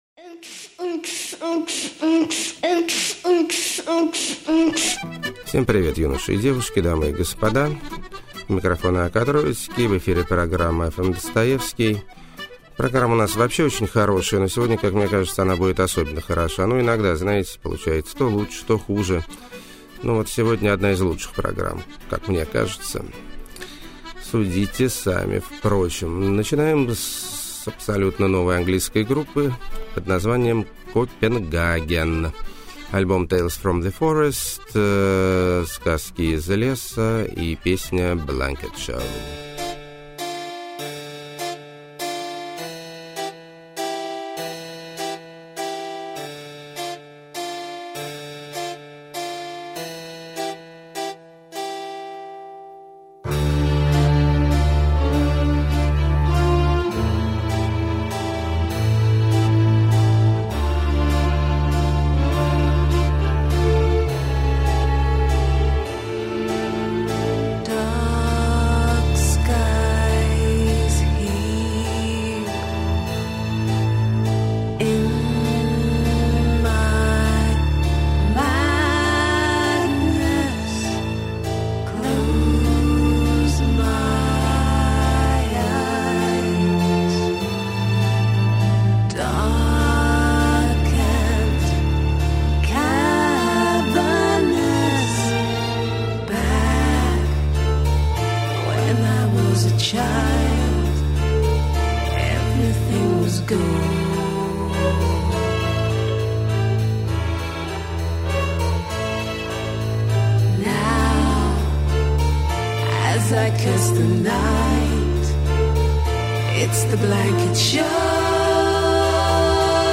Очень Трагический Инди-поп.
Синти-поп Опять Расцвел.] 12.
Классный Мод-рок.
Милый Финский Surf.